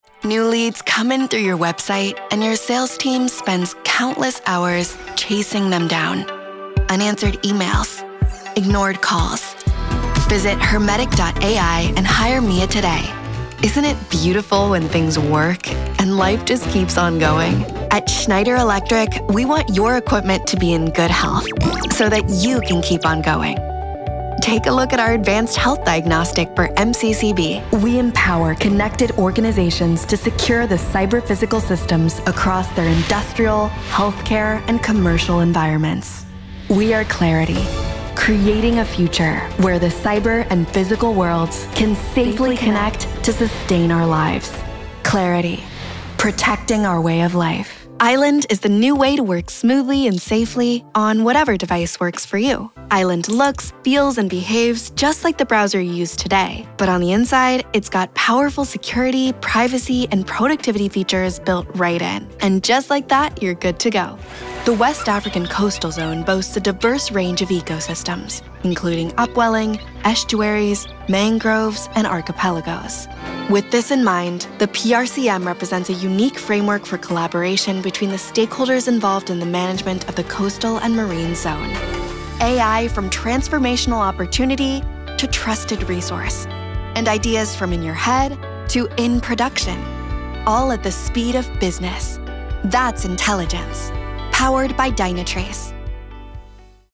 A young, fresh, feminine and soft voice with genuine warmth and clarity
Corporate Reel